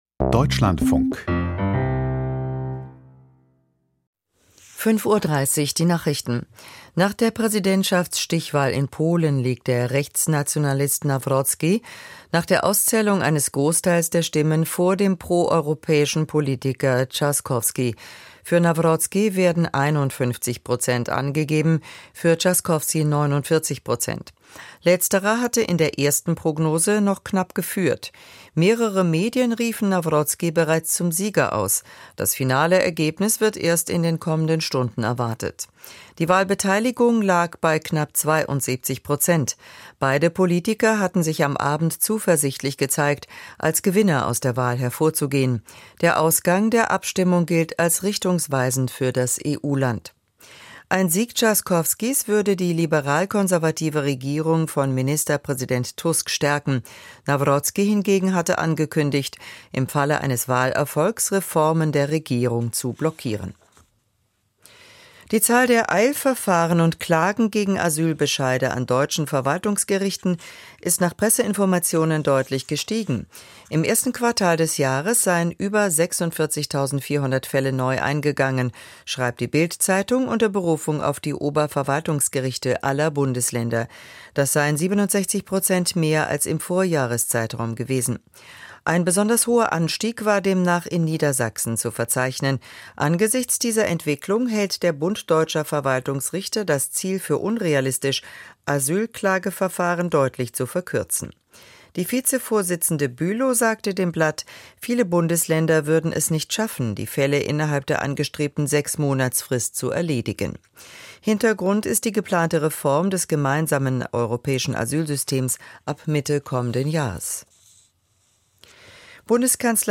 Die Nachrichten vom 02.06.2025, 05:30 Uhr
Aus der Deutschlandfunk-Nachrichtenredaktion.